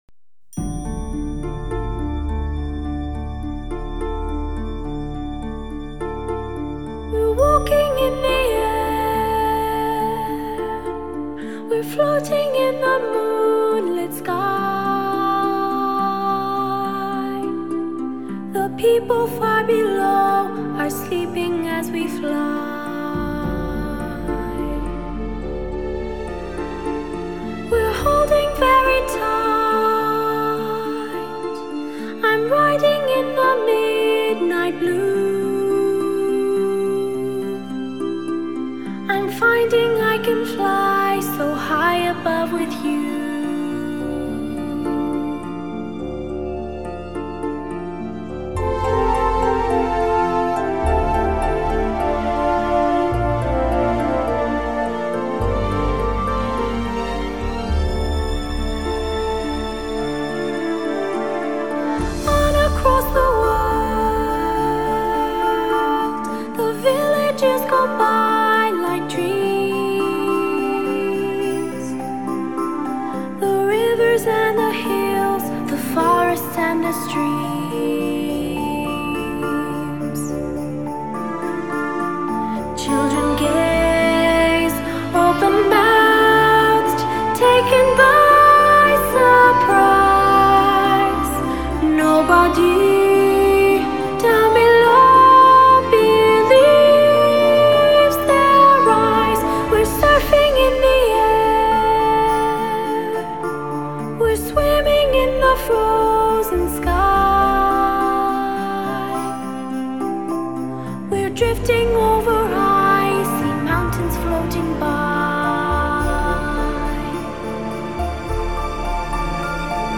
唱片类型：汽车音乐